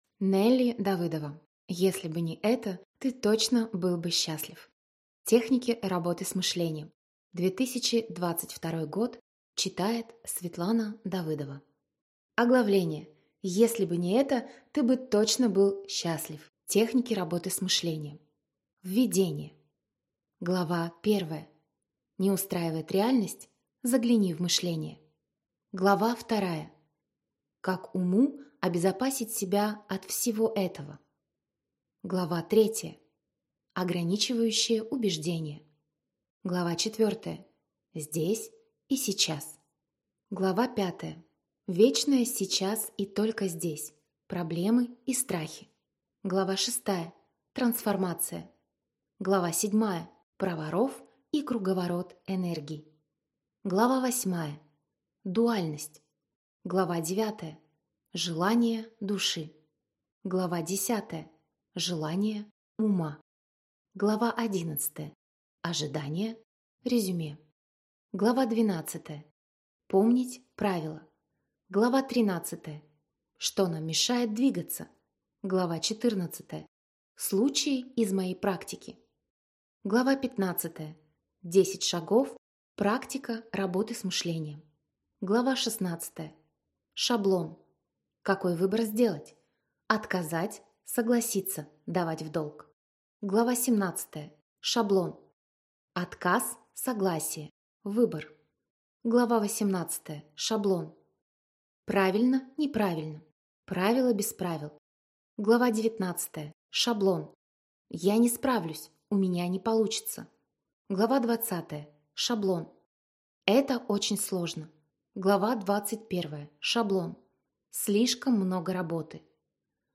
Аудиокнига Если бы не это, ты точно был бы счастлив. Техники работы с мышлением | Библиотека аудиокниг